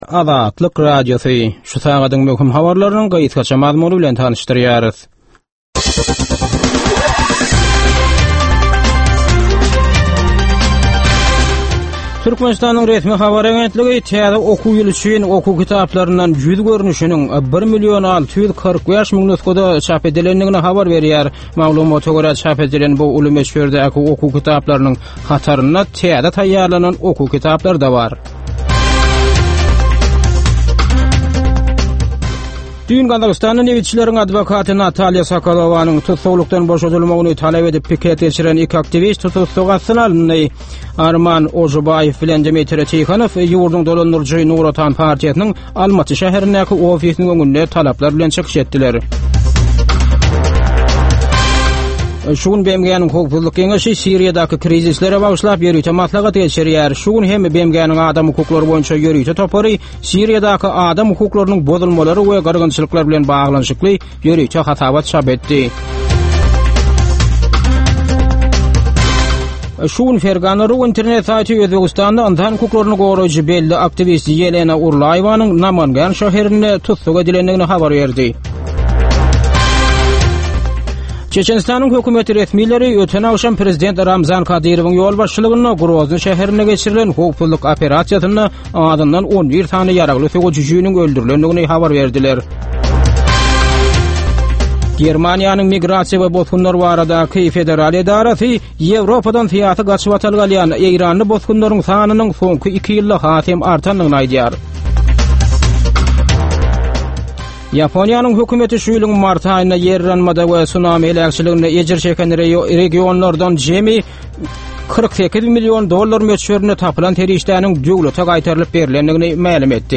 Türkmenistandaky we halkara arenasyndaky möhüm wakalar we meseleler barada ýörite informasion-analitiki programma. Bu programmada soňky möhüm wakalar we meseleler barada analizler, synlar, söhbetdeşlikler, kommentariýalar we diskussiýalar berilýär.